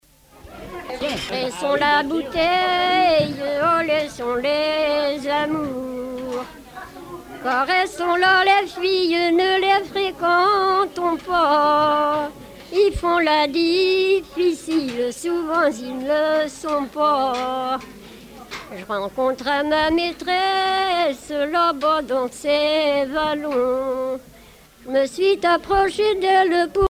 Genre strophique
Chansons traditionnelles